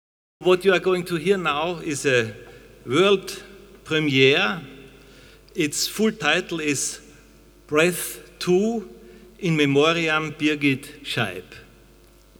Wiener Nobelpreisträgerseminar 2006 Vienna Seminar of Nobel Laureates 2006 Mitschnitt einer Veranstaltung am Donnerstag, dem 29. Juni 2006 im Festsaal des Wiener Rathauses Koordination
Ansage zu "BREATH II" 00:00:12